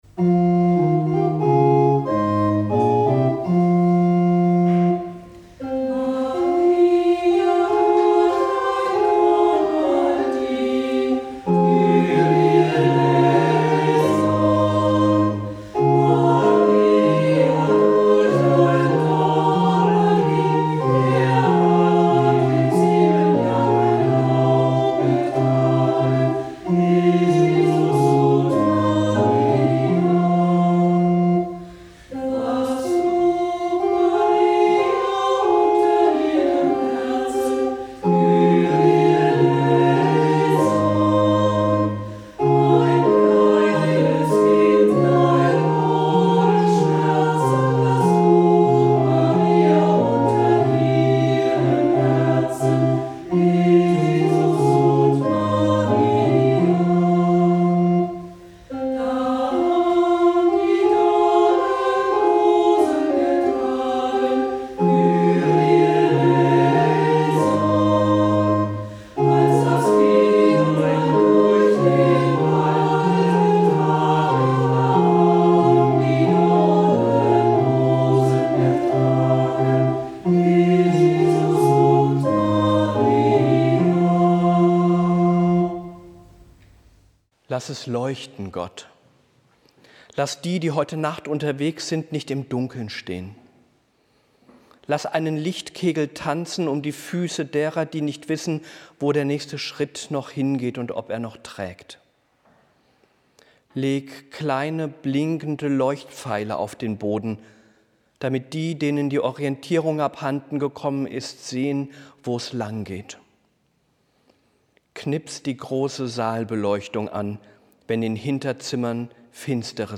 Hörpredigt zu Heiligabend
Hörpredigt_Heilig-Abend_2-2021.mp3